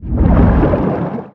Sfx_creature_shadowleviathan_seatruckattack_loop_water_os_03.ogg